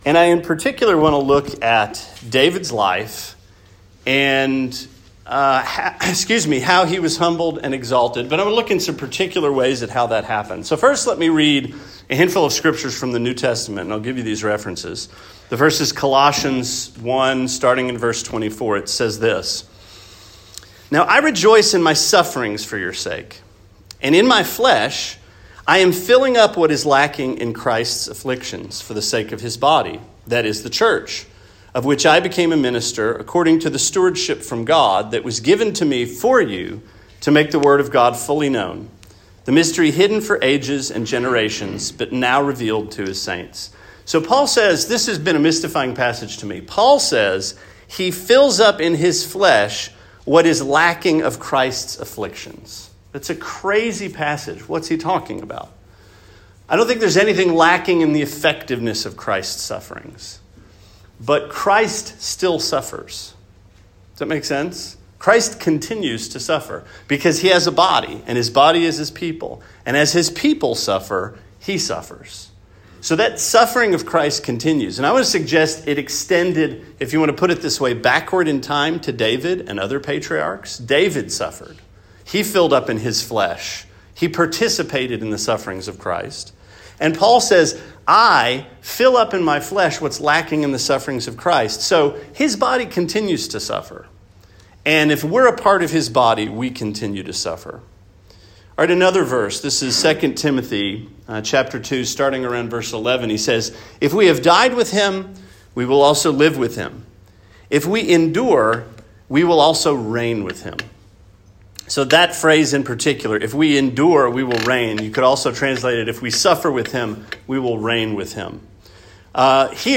Sermon 7/3: The Suffering & Rise of David